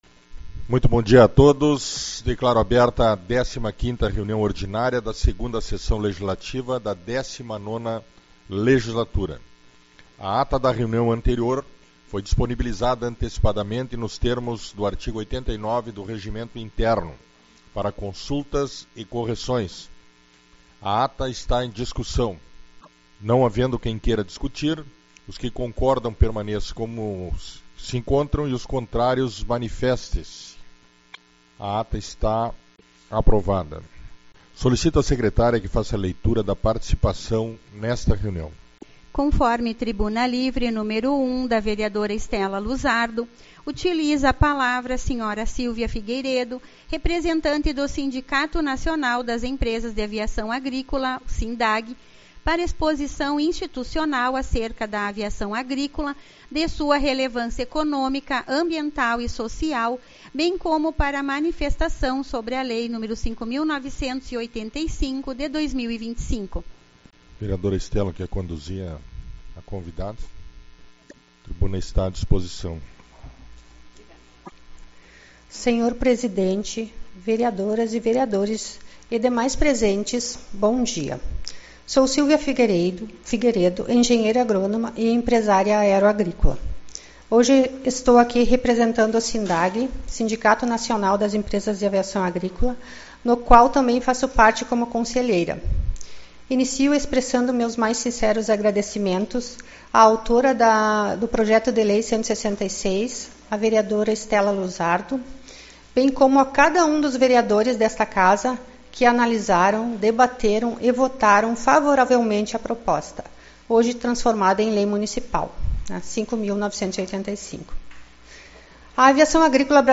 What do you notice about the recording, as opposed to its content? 26/03 - Reunião Ordinária